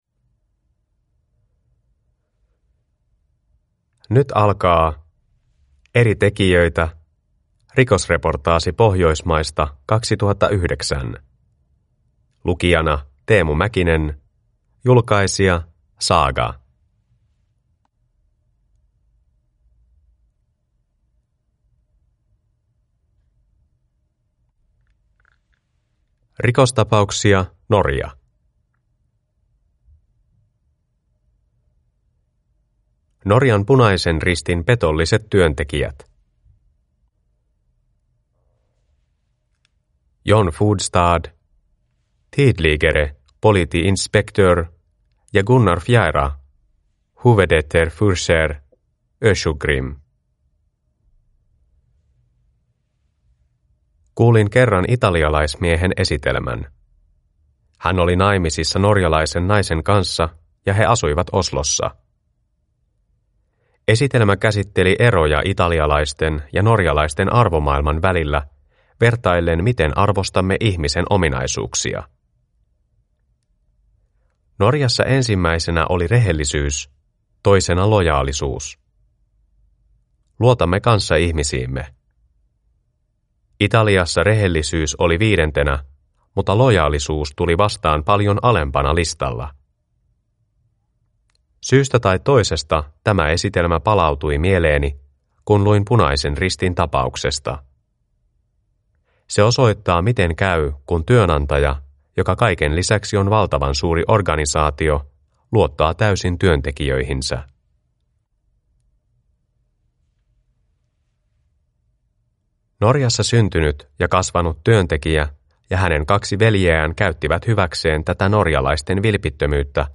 Rikosreportaasi Pohjoismaista 2009 (ljudbok) av Eri tekijöitä